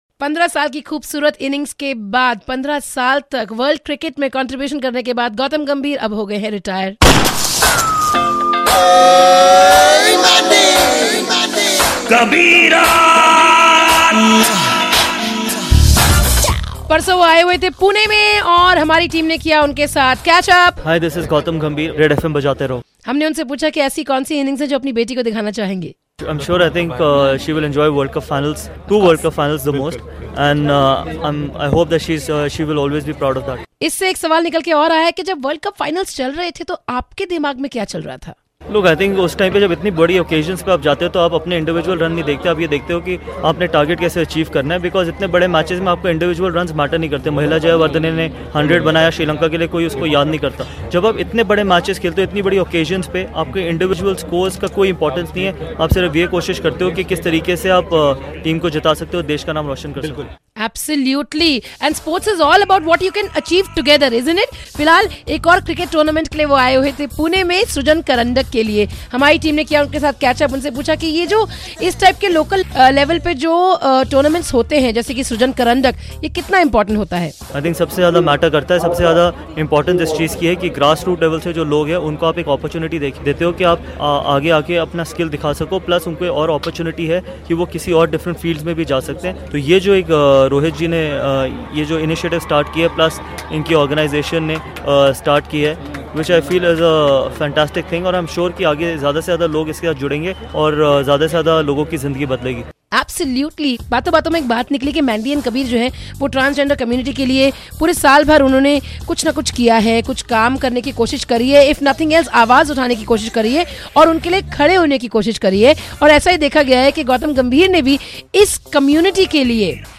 India's World Cup Hero and an immensely talented opener batsman announced his retirement from all forms of cricket. He was in Pune for an event and Red FM team had a really interesting chat with him.